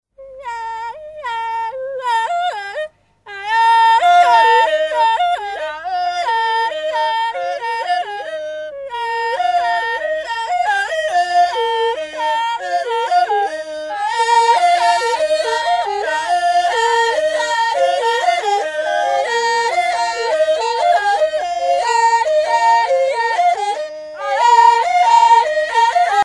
Chants à cinq voix
Jodel polyphonique
Gabon